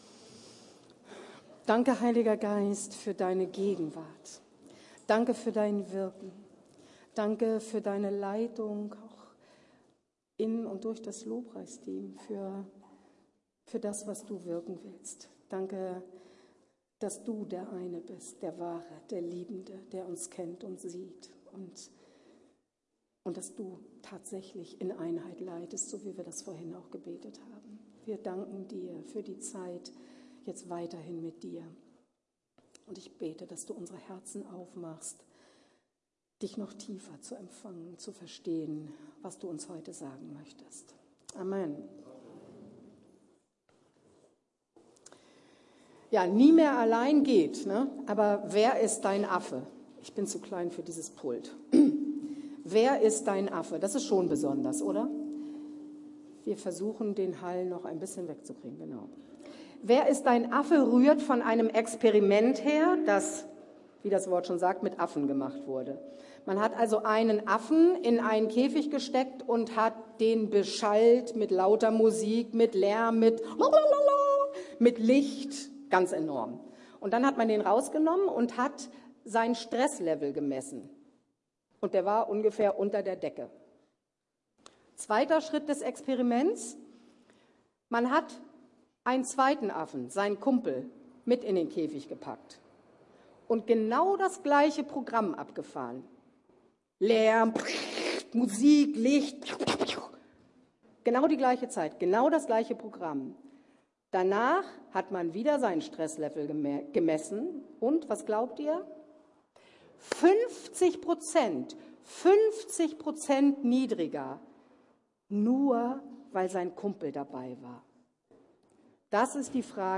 Nie mehr allein. Wer ist dein Affe? ~ Predigten der LUKAS GEMEINDE Podcast